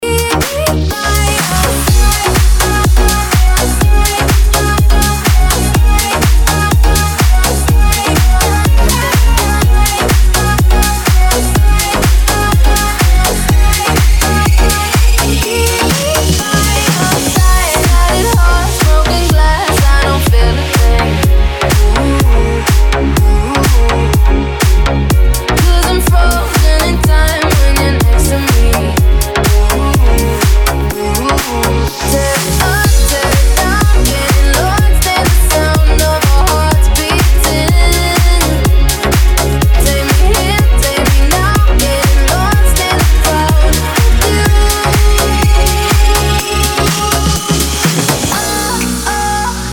из Клубные
Категория - клубные.